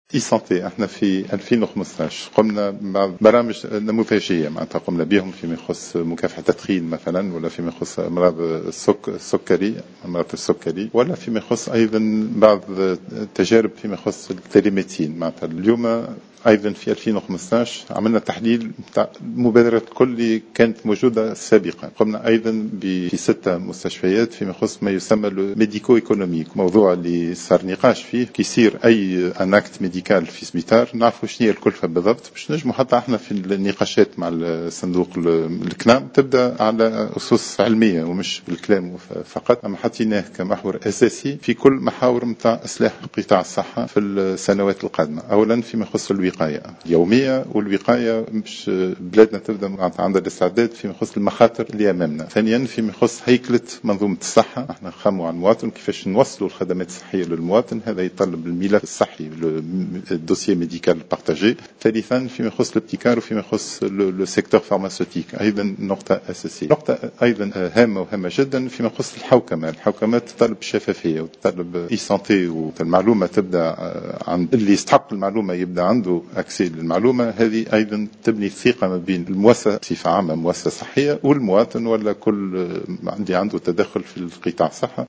وأضاف العايدي في تصريح